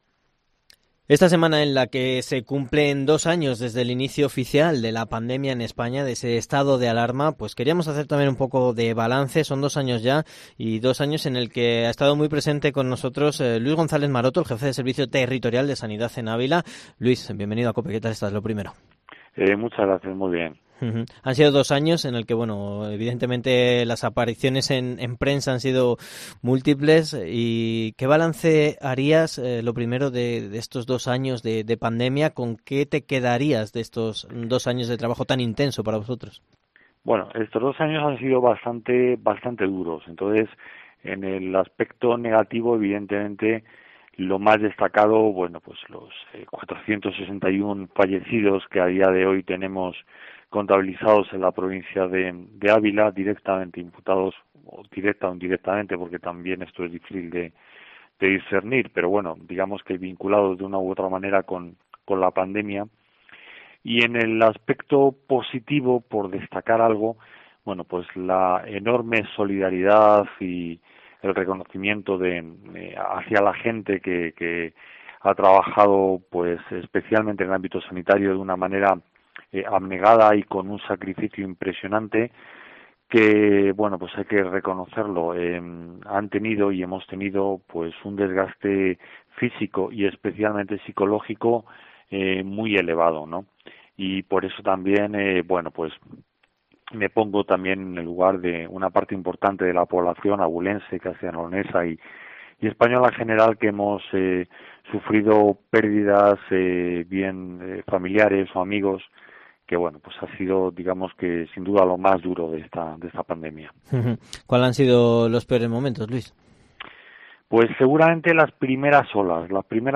Entrevista / El Jefe del Servicio Territorial de Sanidad en Ávila, Luis González Maroto en COPE